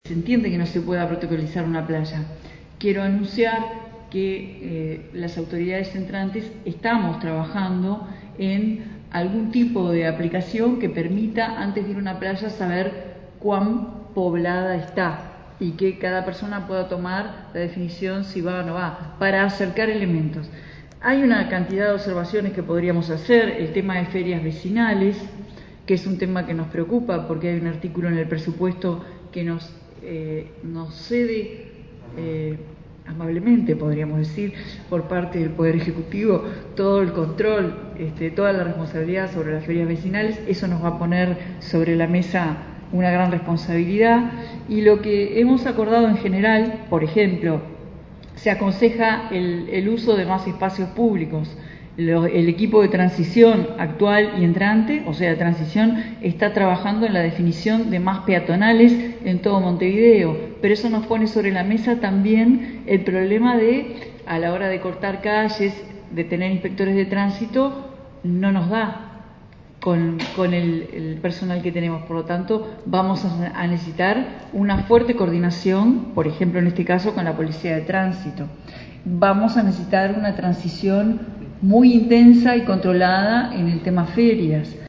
Carolina Cosse, intendenta electa de Montevideo, en el encuentro entre el Cecoed y el gobierno afirmó que entienden que no se pueda protocolizar una playa y agregó que las autoridades entrantes trabajan en algún tipo de aplicación que permita antes de ir a una playa saber cuan poblada está y que cada persona pueda tomar la definición de si va o no va.